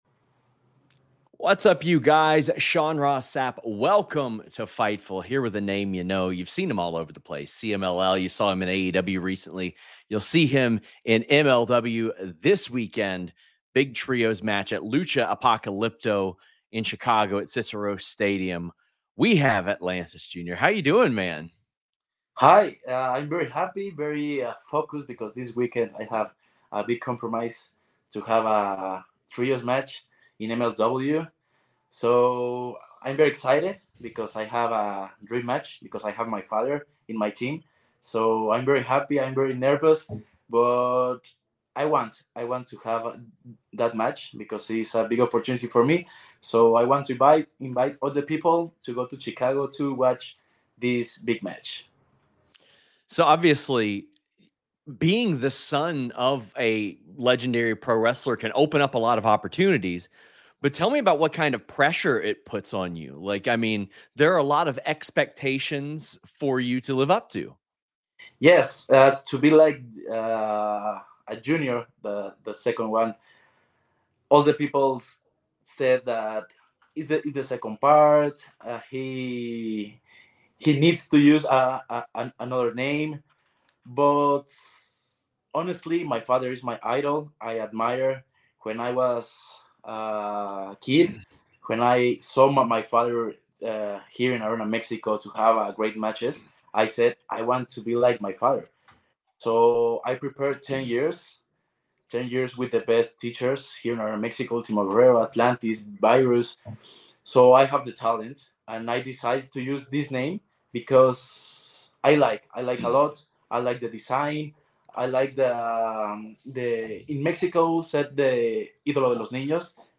Interview | Fightful News